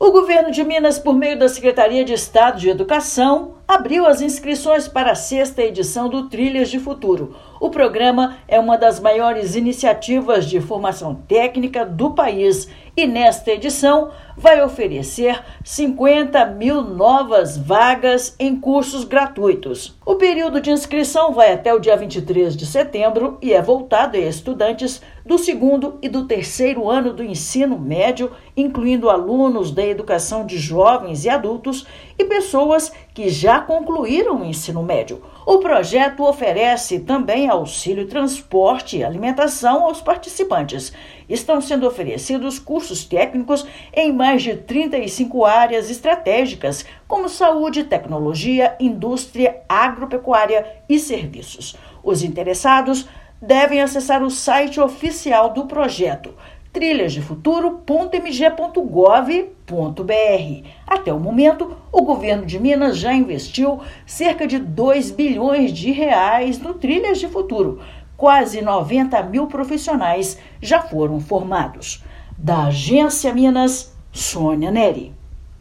Projeto chega à sexta edição com oportunidades em mais de 35 áreas e distribuição de vagas de acordo com as demandas regionais; neste ano, chatbot vai apoiar candidatos no processo de inscrição. Ouça matéria de rádio.